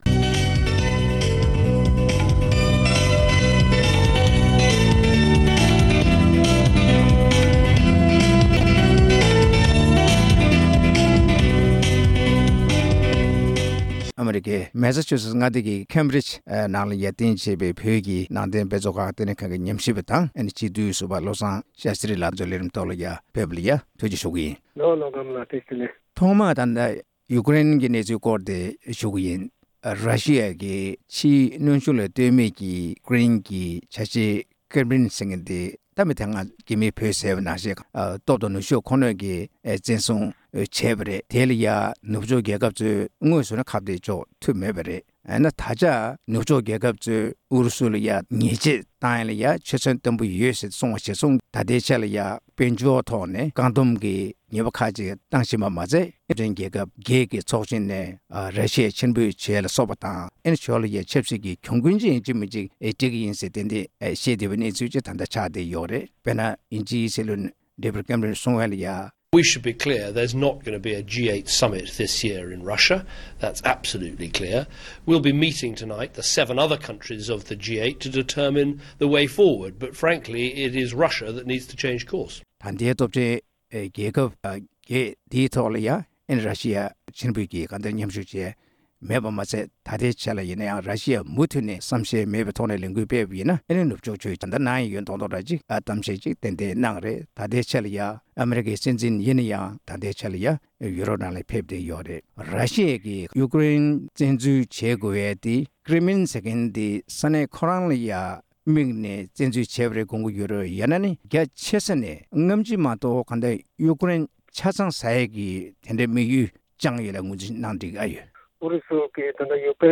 གླེང་མོལ་ཞུས་པ་ཞིག་གསན་གནང་གི་རེད༎